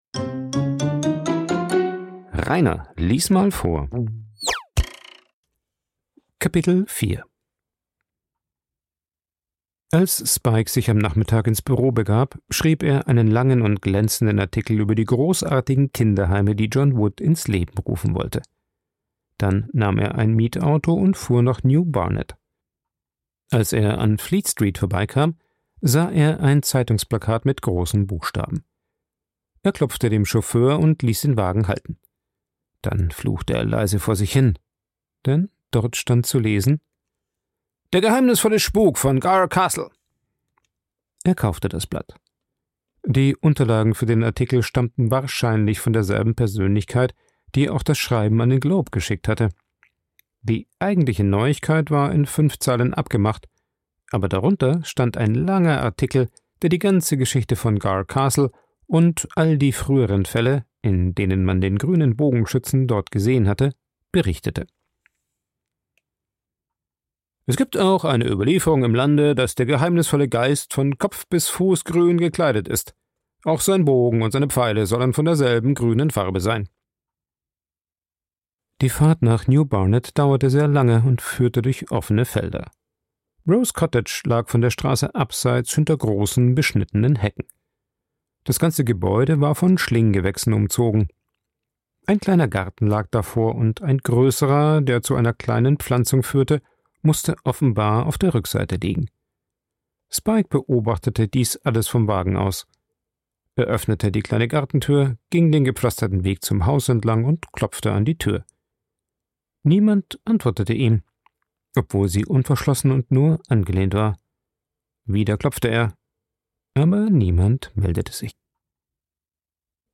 aufgenommen und bearbeitet im Coworking Space Rayaworx, Santanyí, Mallorca.